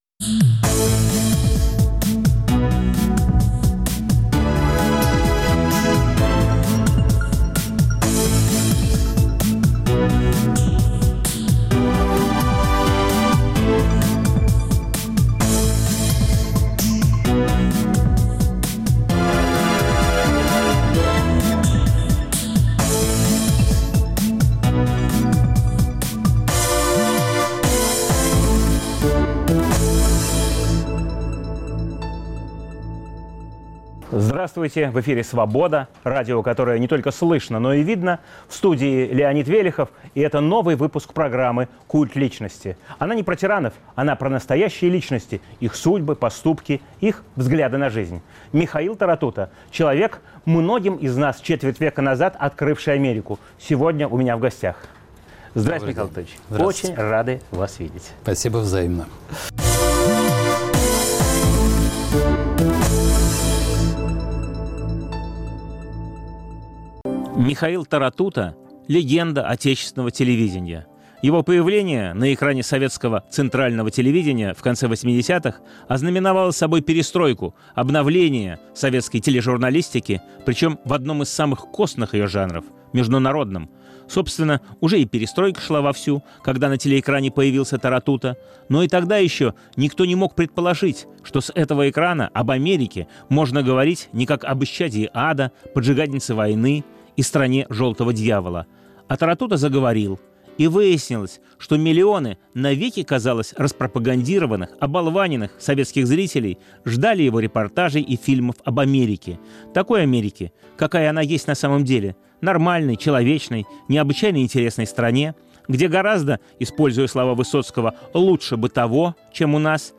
Новый выпуск программы о настоящих личностях, их судьбах, поступках и взглядах на жизнь. В студии легенда отечественного телевидения, Михаил Таратута.